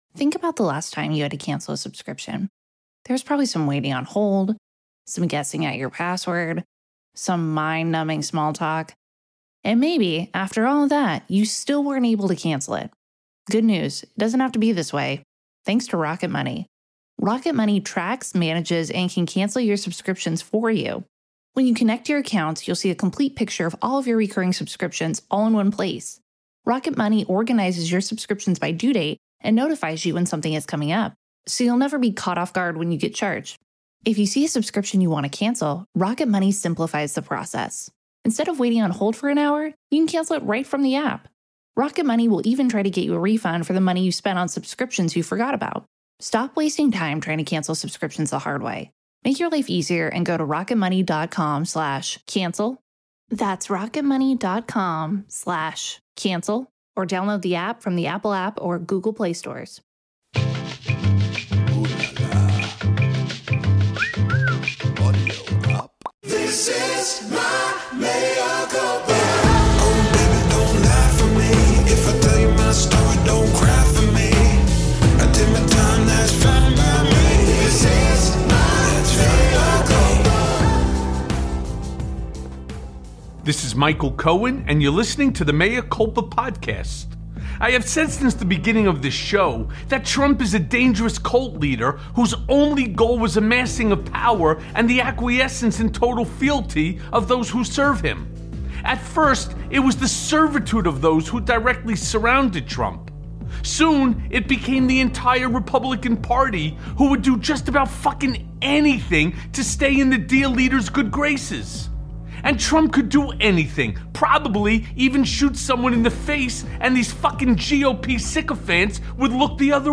Special Report!! The Making of a MAGA Fanatic + A Conversation with Cult Expert Dr. Steven Hassan
In this special episode of Mea Culpa Michael speaks to renowned cult expert Dr. Steven Hassan on the cult of Donald Trump.